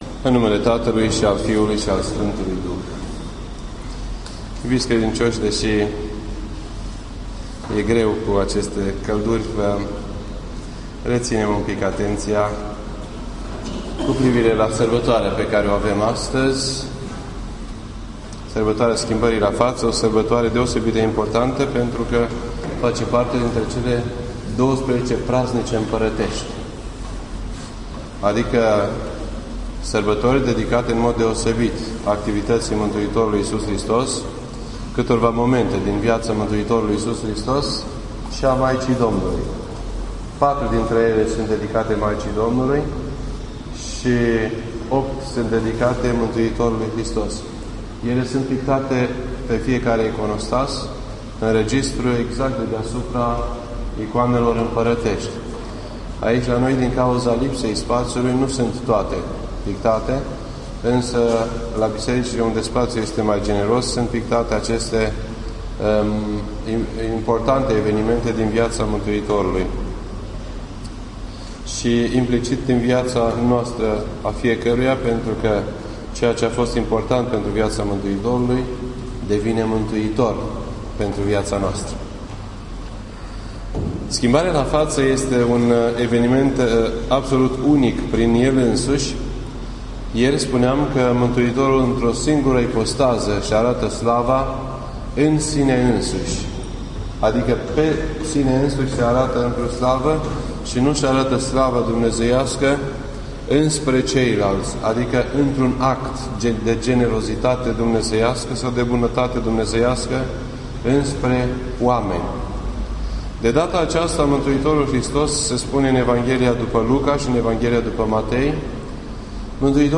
2012 at 7:45 PM and is filed under Predici ortodoxe in format audio .